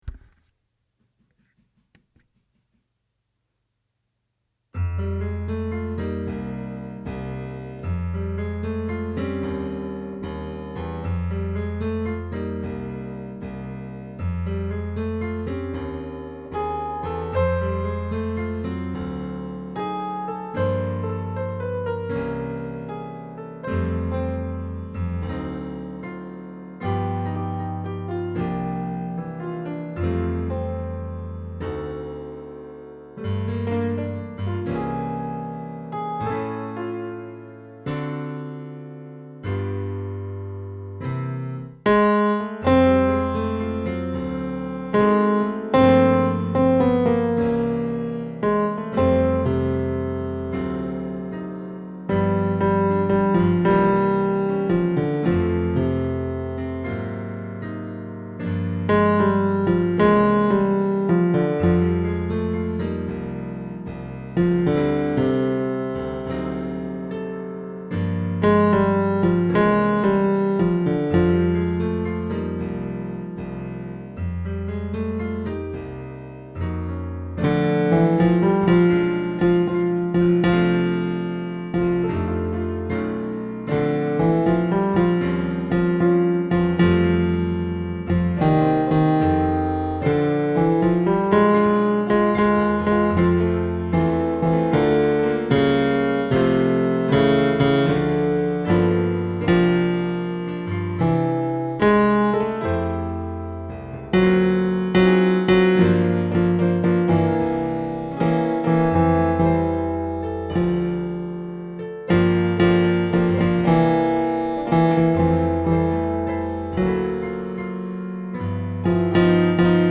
stormy-weather-bass.mp3